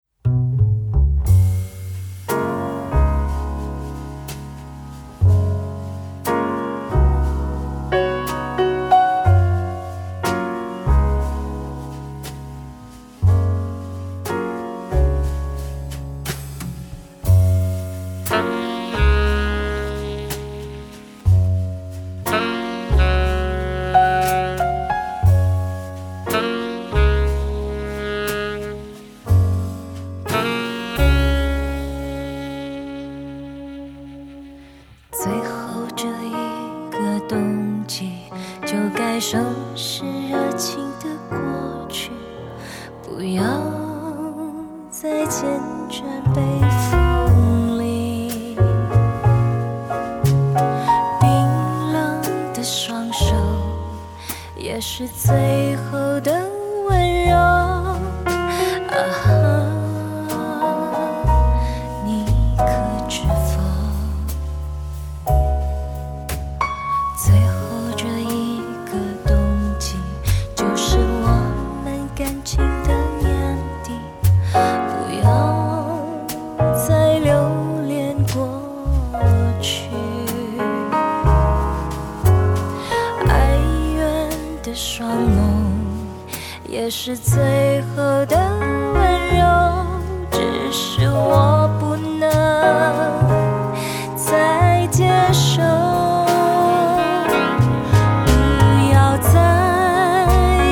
寧靜背景中展現如臨現場的自然活生感！